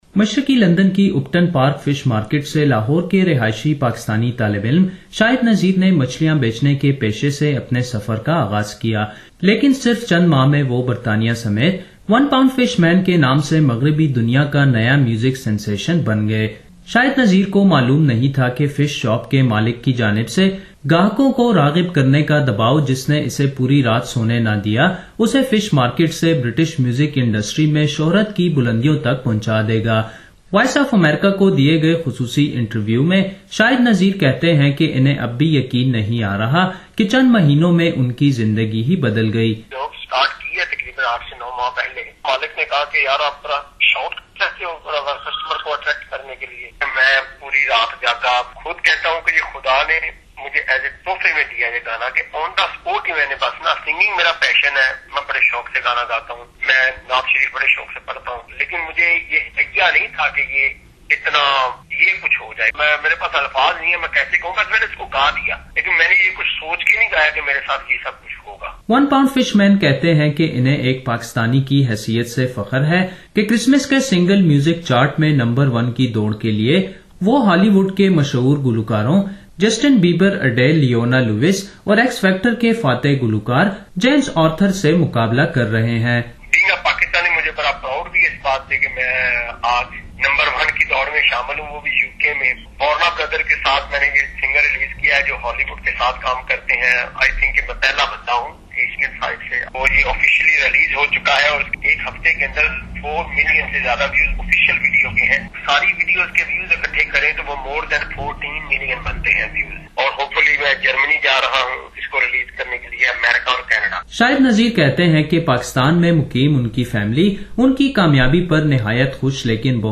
ون پونڈ فش سٹار شاہد نذیر سے ملاقات